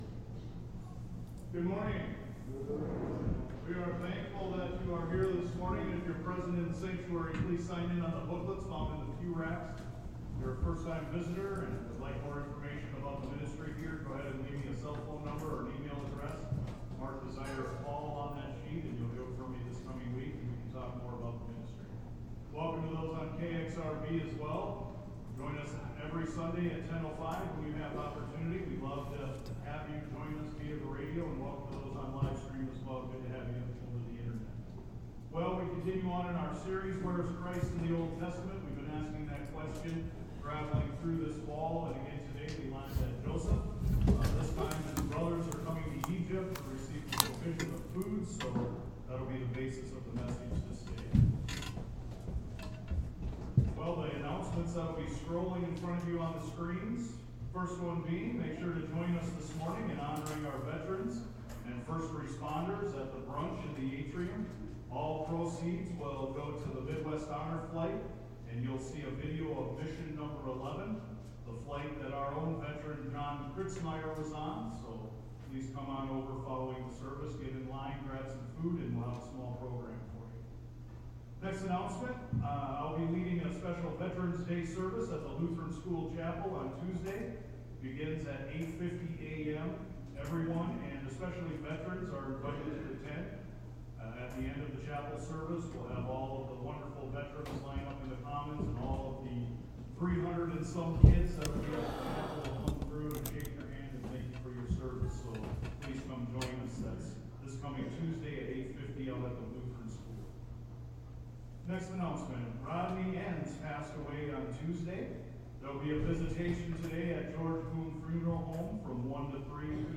SERMON-NOVEMBER-9-2025.mp3